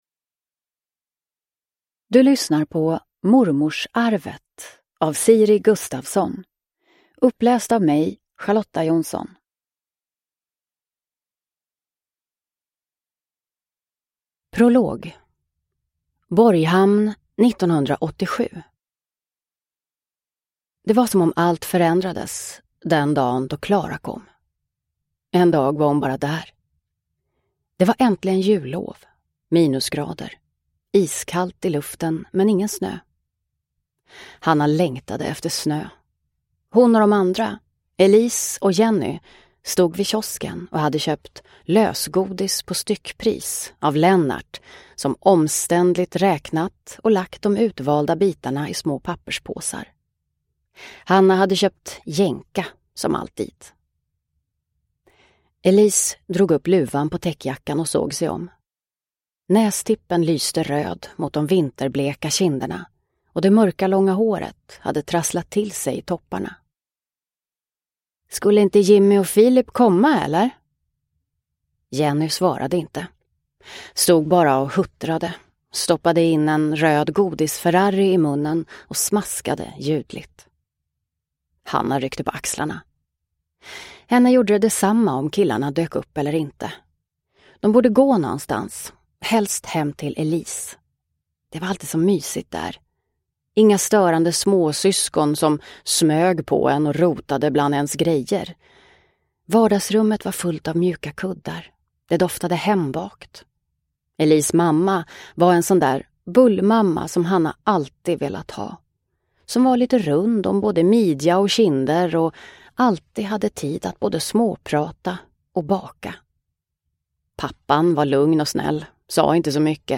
Mormorsarvet – Ljudbok – Laddas ner